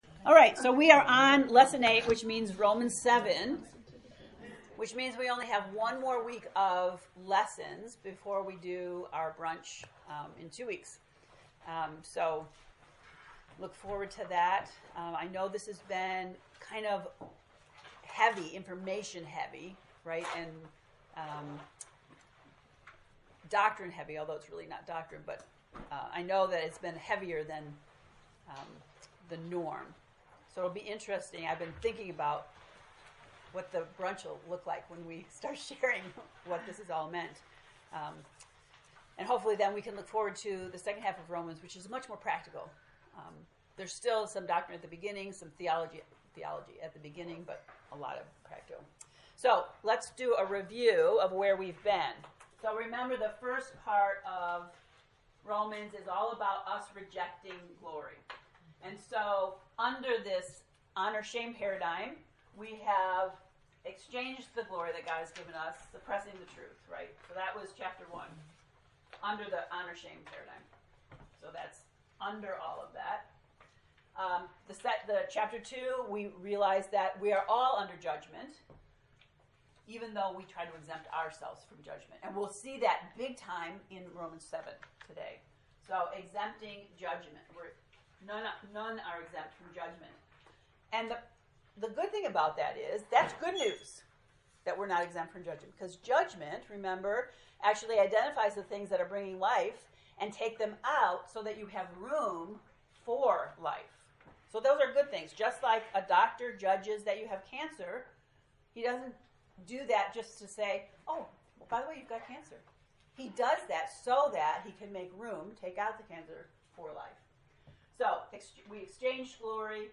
To listen to the lesson 8 lecture, “Joined by the Spirit,” click below: